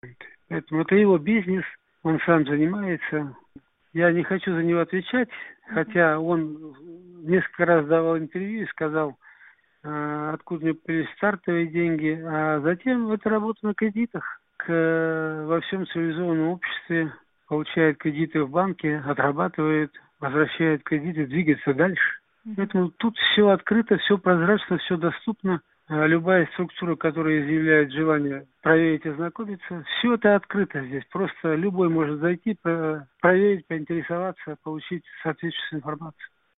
Политэмигрант Виктор Храпунов, живущий в Швейцарии бывший топ-чиновник Казахстана, дал интервью Азаттыку.